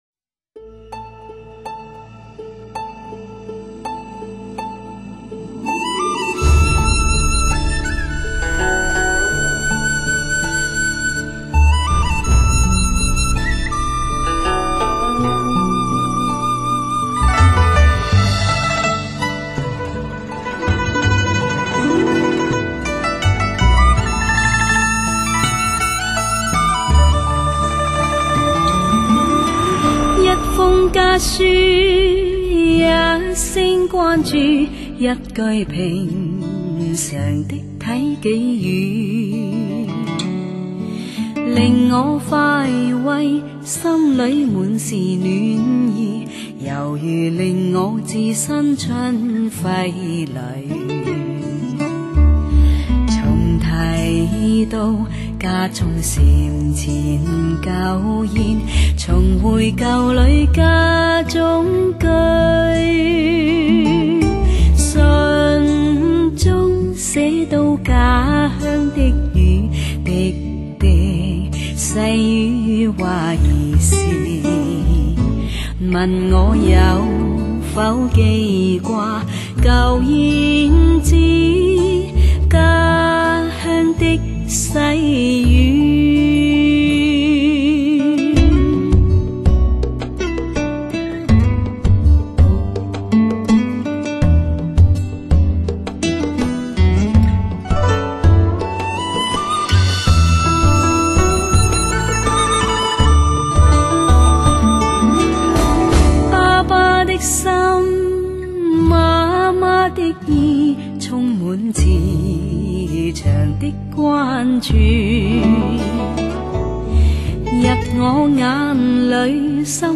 典雅醇厚 感人至深
独具一格的演唱功力，定位精准，音色通透自然，音乐更显婉转隽永，
整张专辑的催眠功力超级强大松软绵厚的声音让人从精神到肉体彻底放松，